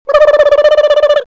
Vocal FUNNY
Category 🗣 Voices